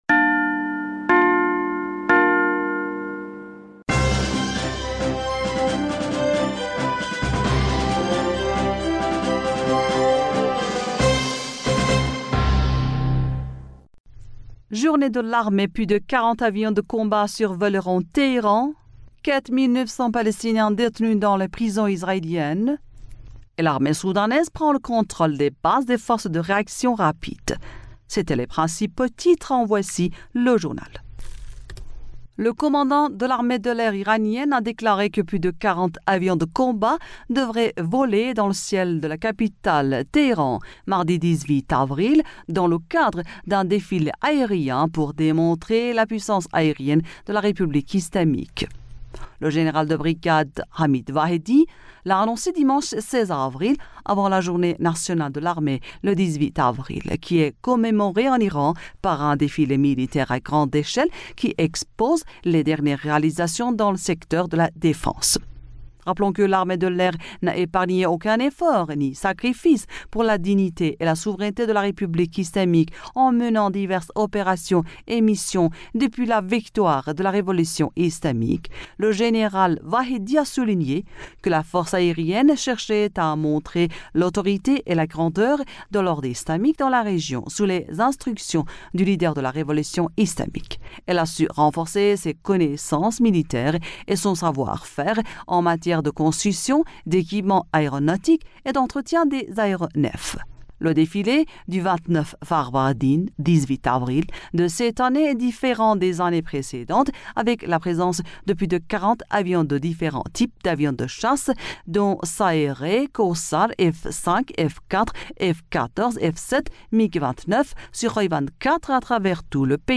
Bulletin d'information du 17 Avril 2023